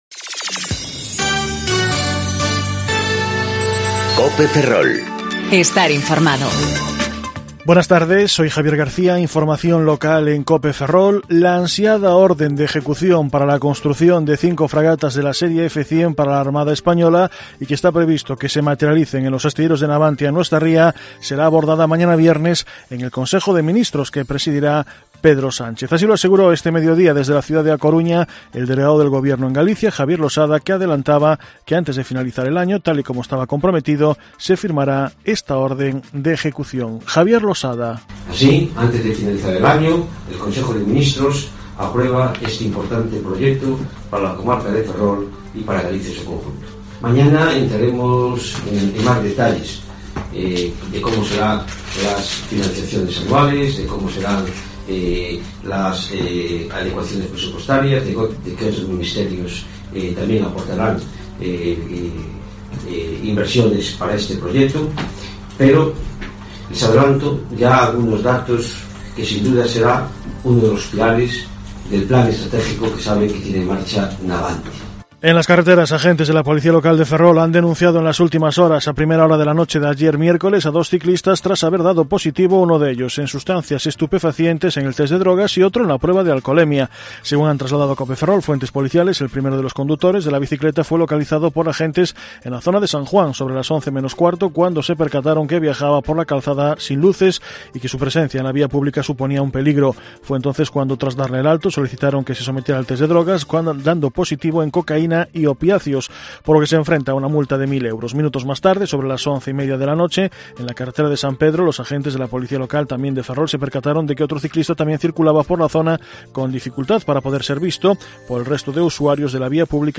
Informativo Mediodía Cope Ferrol